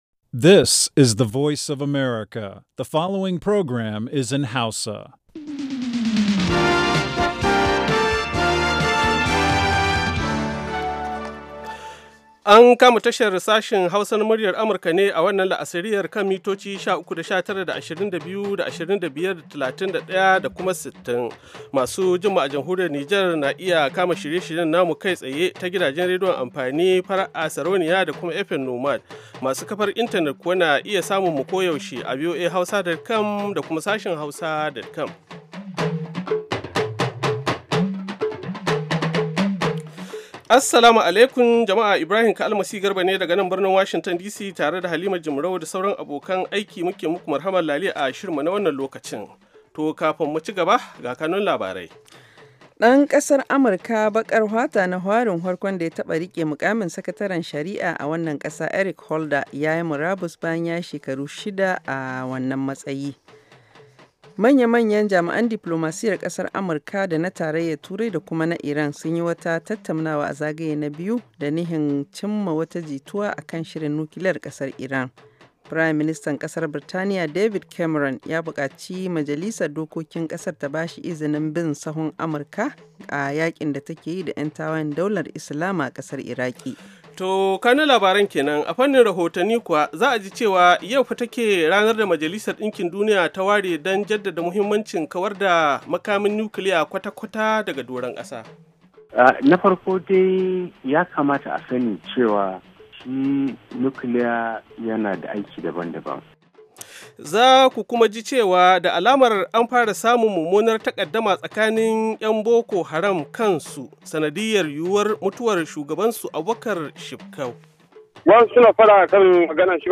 Da karfe 4 na yamma agogon Najeriya da Nijar zaku iya jin rahotanni da labarai da dumi-duminsu daga kowace kusurwa ta duniya, musamman ma dai muhimman abubuwan da suka faru, ko suke faruwa a kusa da ku a wannan rana.